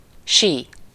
Ääntäminen
Ääntäminen France: IPA: [ski] Haettu sana löytyi näillä lähdekielillä: ranska Käännös Ääninäyte Substantiivit 1. sí Suku: m .